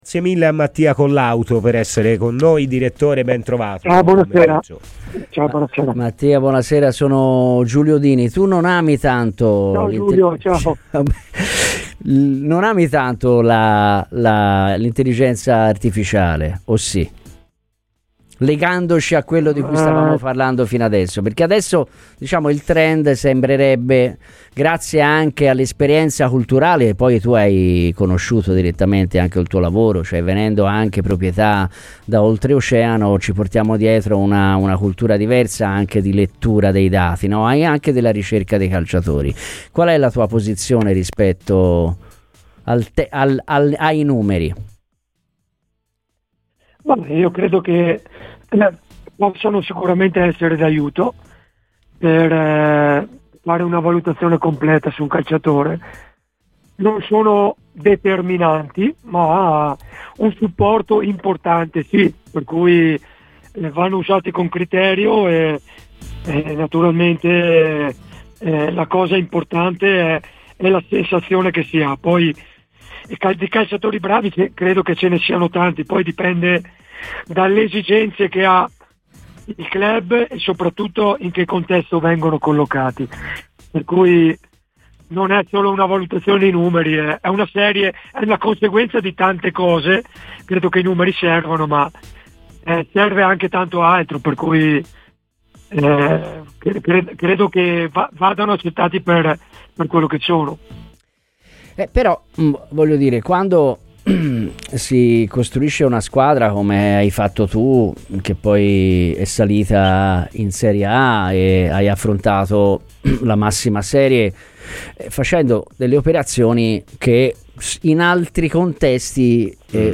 durante la trasmissione di approfondimento Scanner